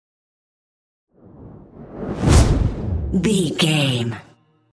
Whoosh fire ball
Sound Effects
whoosh